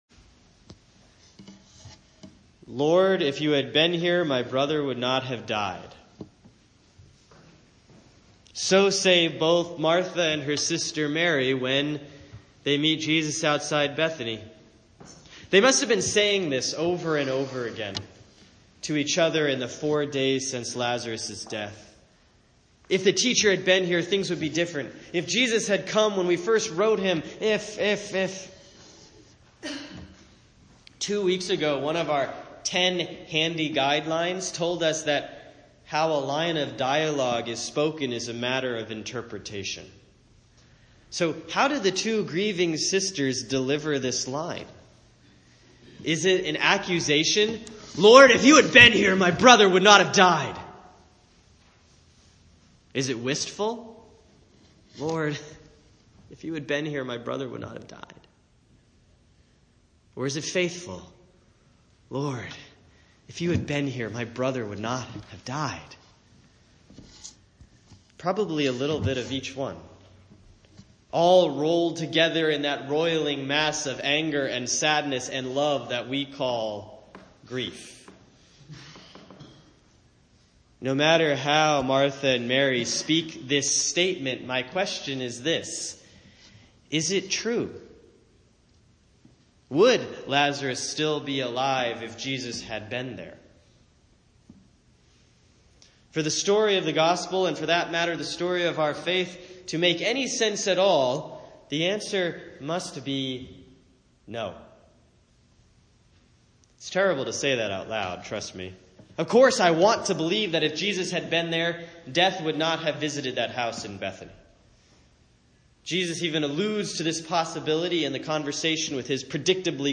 Sermon for Sunday, April 2, 2017 || Lent 5A || John 11:1-45